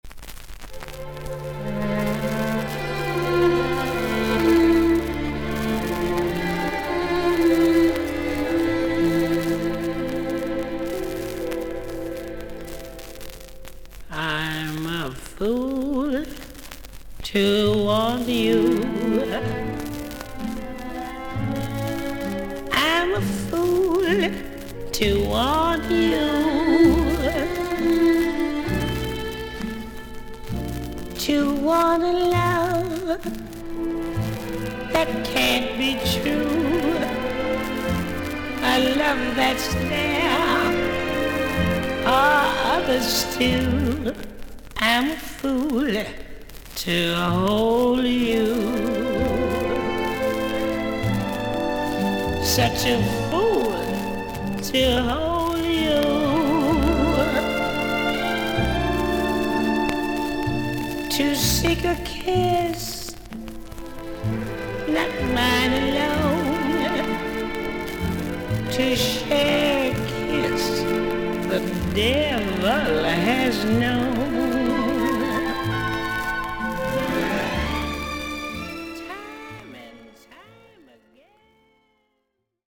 所々に軽いパチノイズの箇所あり。全体的に大きめのサーフィス・ノイズあり。
アメリカを代表する女性ジャズ・シンガー。